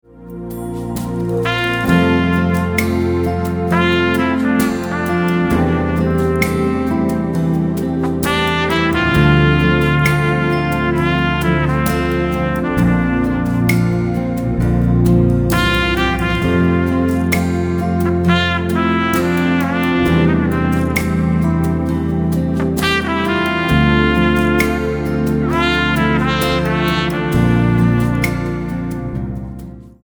Instrumental-CD